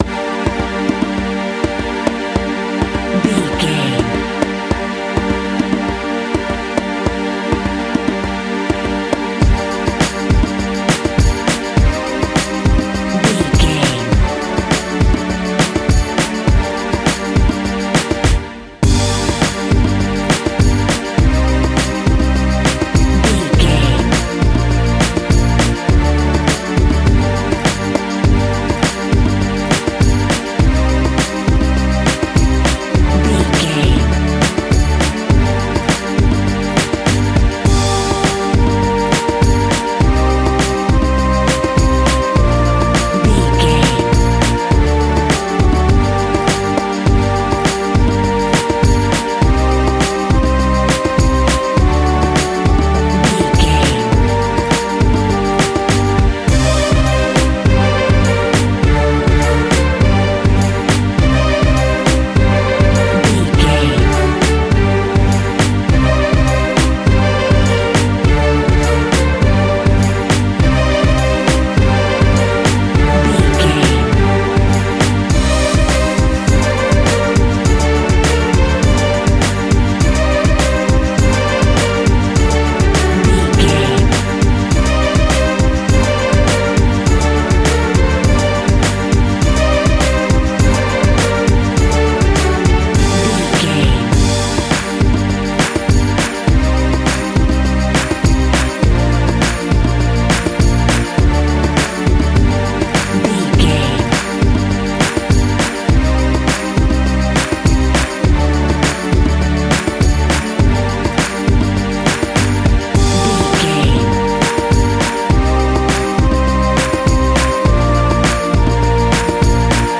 Aeolian/Minor
instrumentals
World Music
percussion